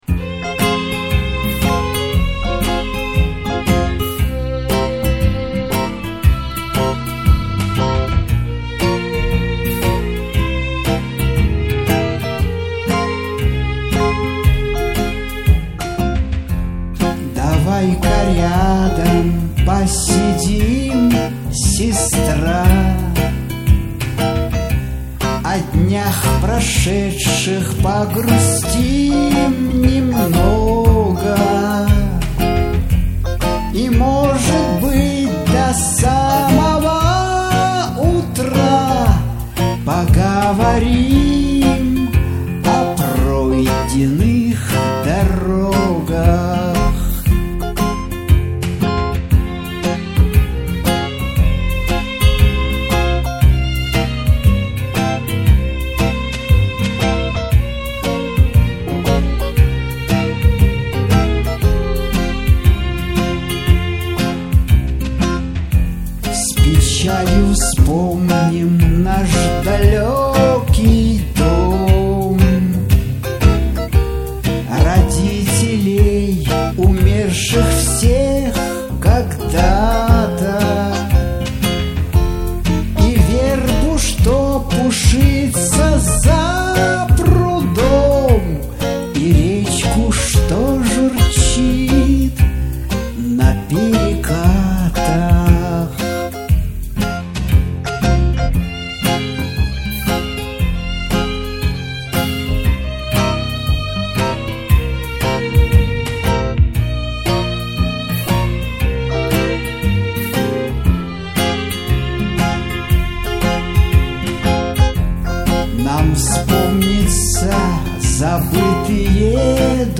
Лирическая музыка